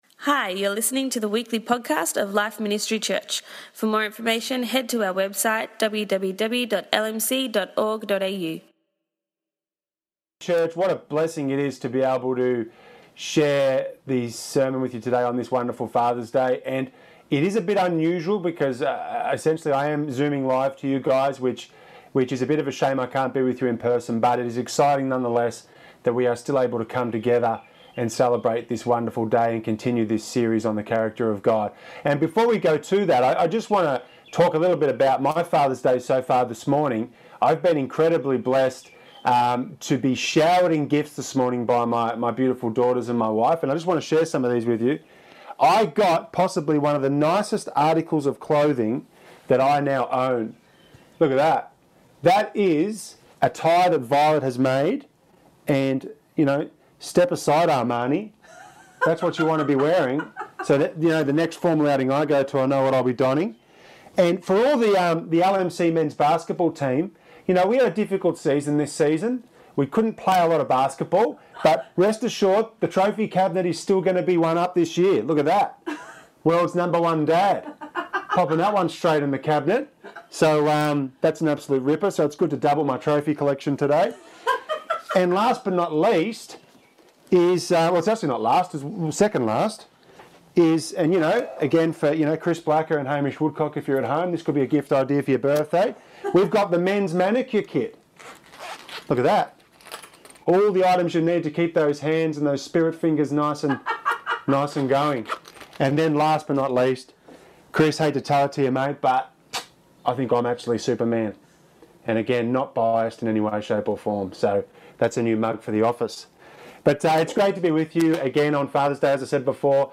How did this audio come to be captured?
a compelling Father's Day message, encouraging us to emulate Godly wisdom in being slow to anger.